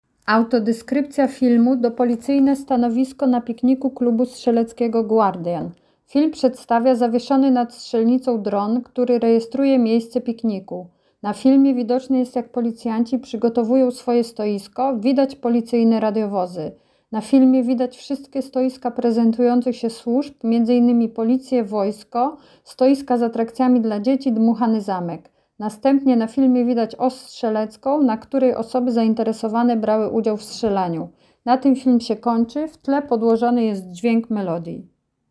Nagranie audio Autodeskrypcja filmu do policyjne stanowisko na pikniku Klubu Strzeleckiego Guardian.